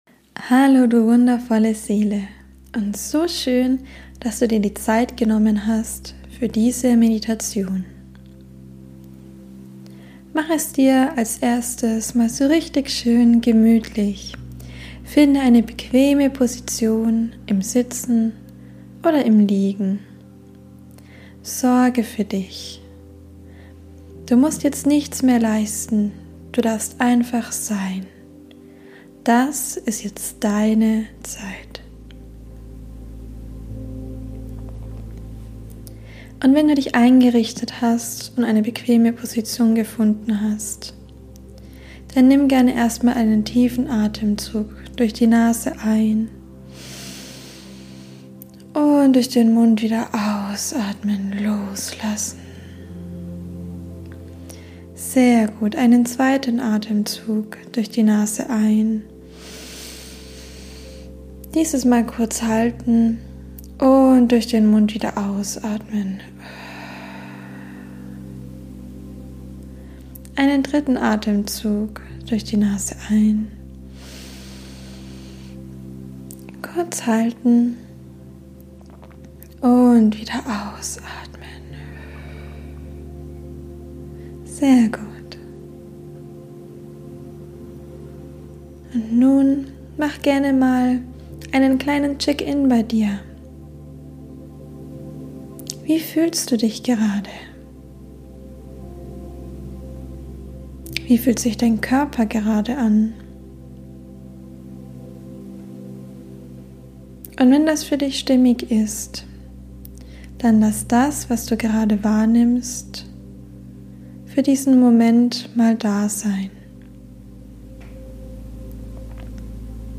#12 Einschlafmeditation - Entspannen, Energien loslassen, Einschlafen ~ Lust auf Leben Podcast